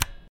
button-up.mp3